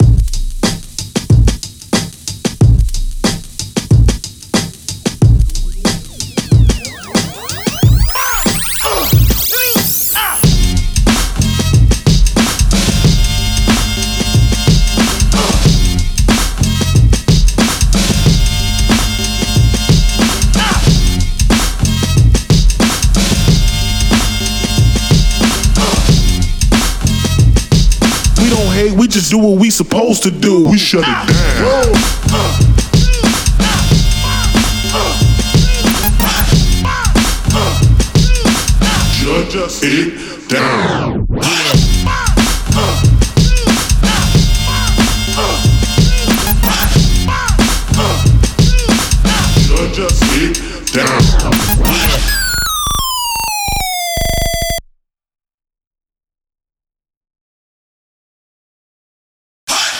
Here are all the beats used for the night.”